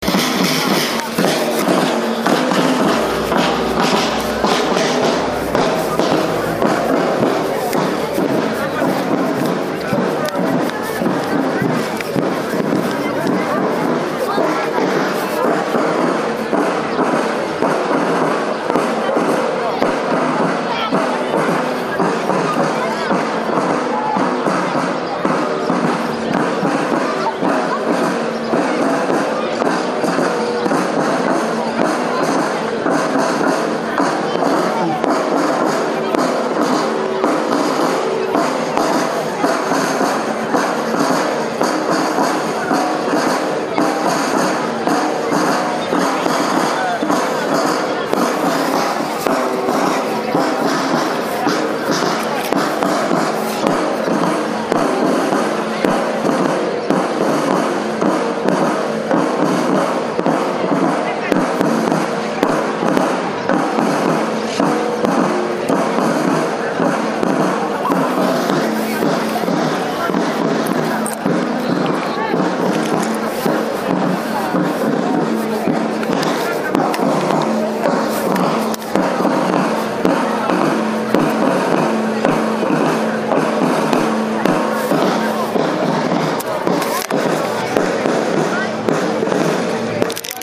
153º ANIVERSARIO DE RAMALLO. ACTO CENTRAL EN LA PLAZA PRINCIPAL JOSÉ MARÍA BUSTOS.
Un acto protocolar muy prolijo y coqueto vivimos junto con toda la gente que participó del mismo.
Audio: Entrada de la Banda Popular Infanto Juvenil